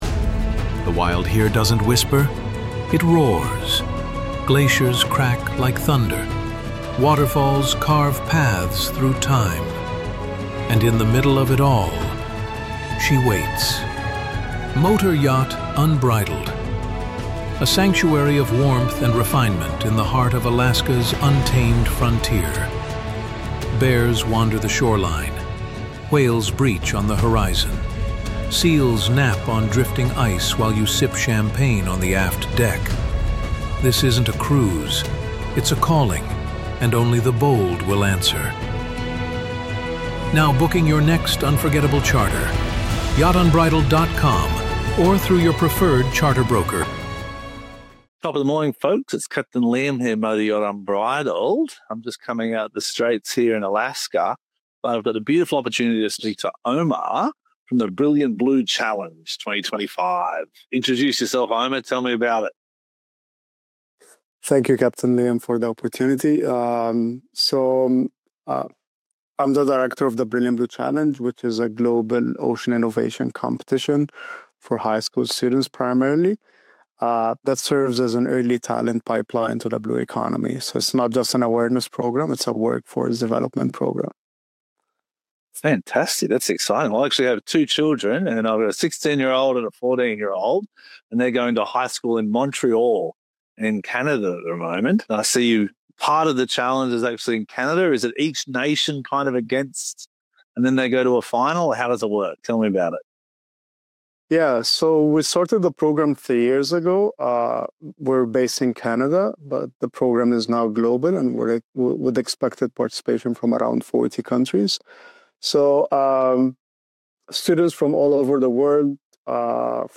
Captain’s Chat